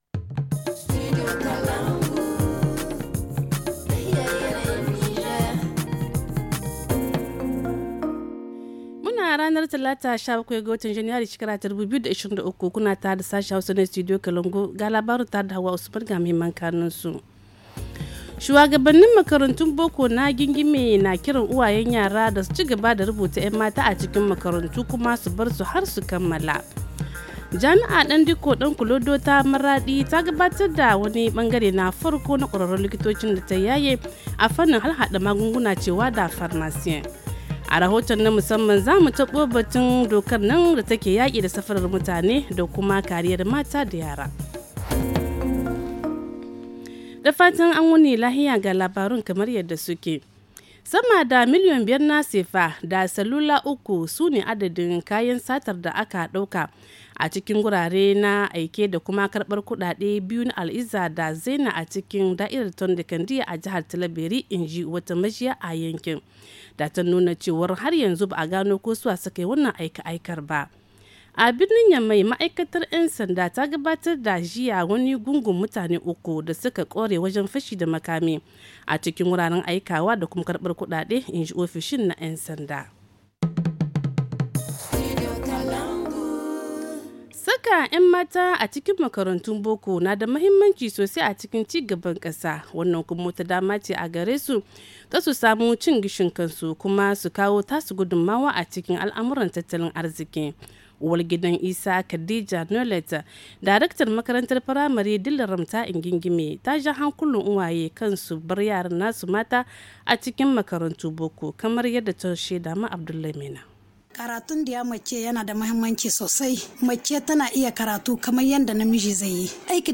Le journal du 17 janvier 2023 - Studio Kalangou - Au rythme du Niger